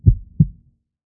heart_beats